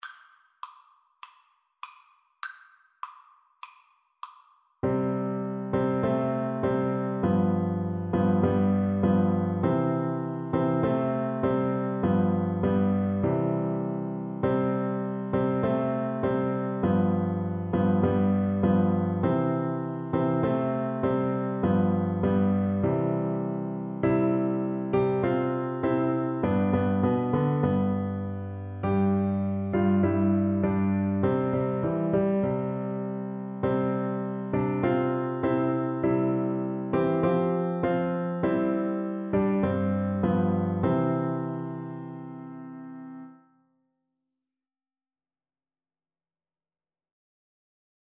4/4 (View more 4/4 Music)
C6-C7
Classical (View more Classical Flute Music)